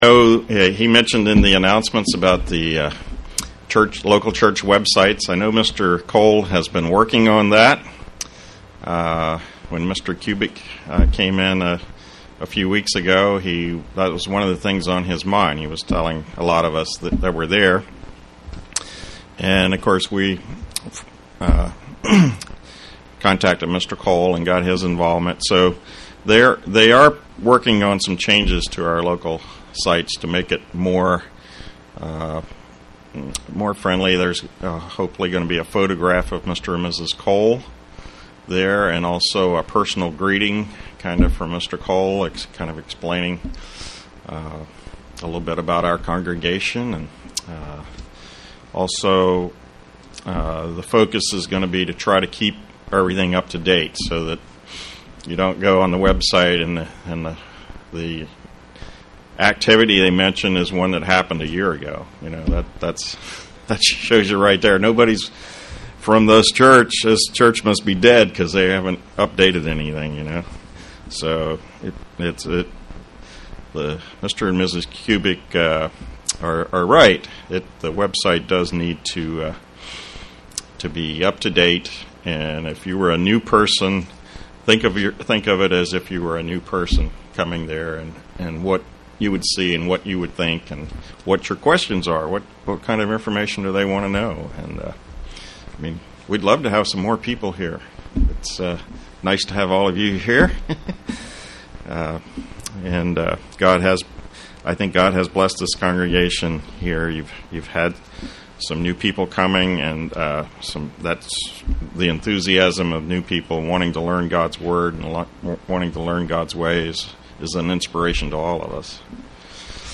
Given in York, PA
UCG Sermon Studying the bible?